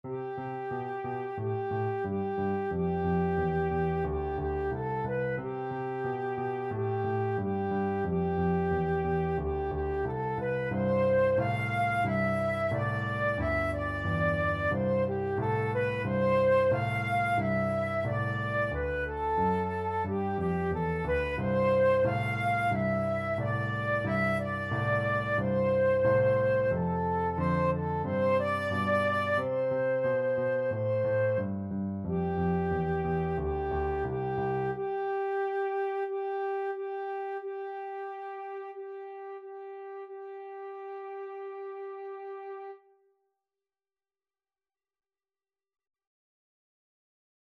A beginners piece with a rock-like descending bass line.
March-like = 90
Flute  (View more Beginners Flute Music)
Pop (View more Pop Flute Music)